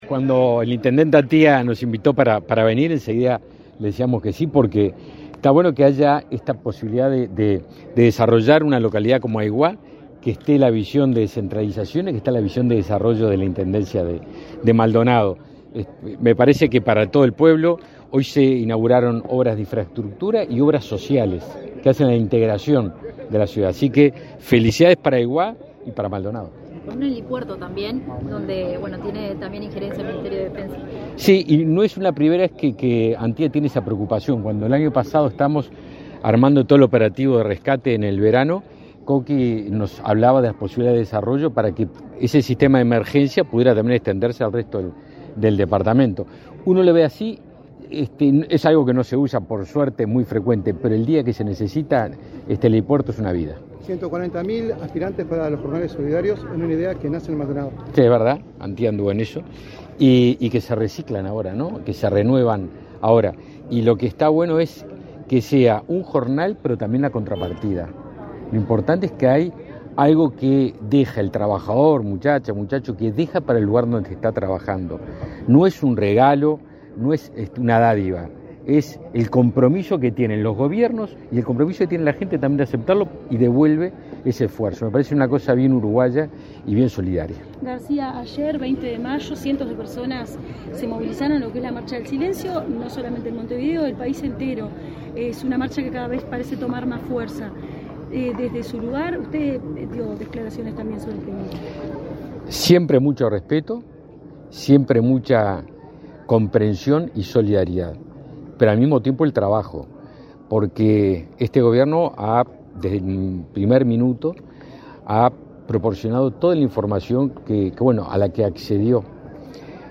Declaraciones a la prensa del ministro de Defensa Nacional, Javier García
Declaraciones a la prensa del ministro de Defensa Nacional, Javier García 22/05/2023 Compartir Facebook X Copiar enlace WhatsApp LinkedIn El Gobierno inauguró obras en Aiguá, en el marco del 117.° aniversario de la localidad, este 22 de mayo. Tras el evento, el ministro de Defensa Nacional, Javier García, realizó declaraciones a la prensa.